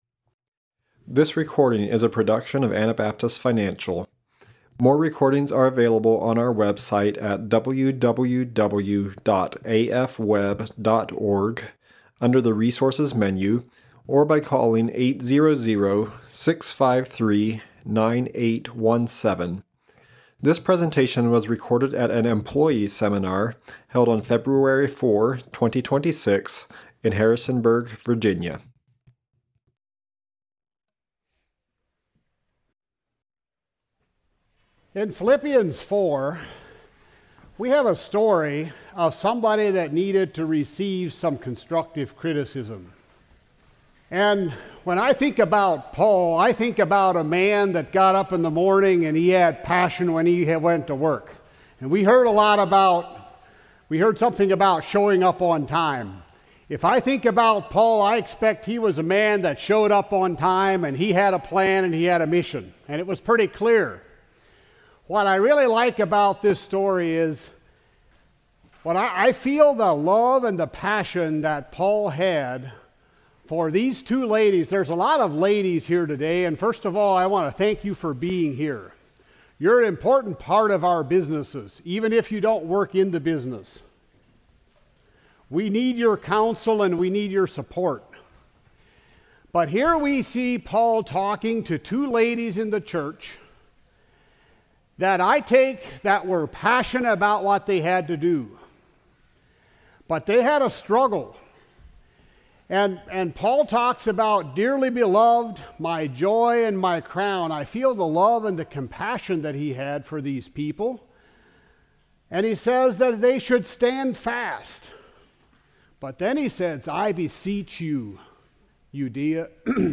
Virginia Employee Seminar 2026